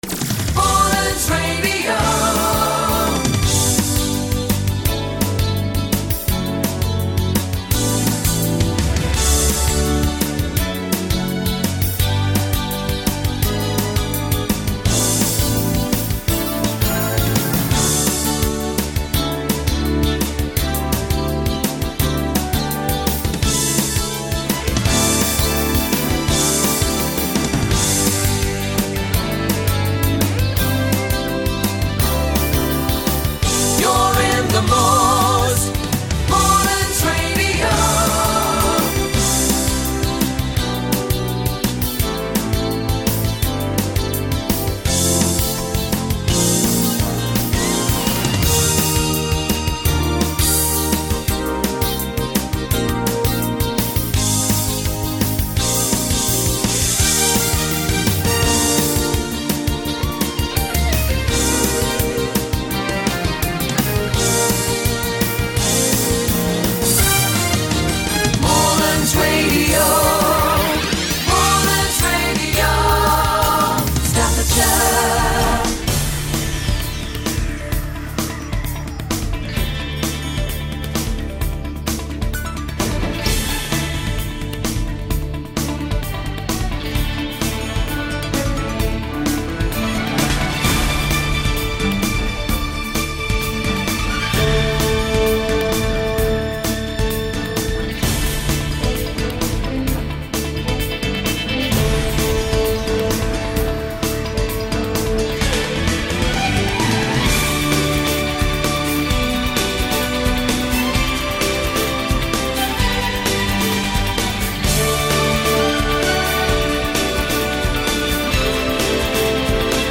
So, on Saturday Mornings, before 7am, we start with 2 tunes.
with most of the singing taken out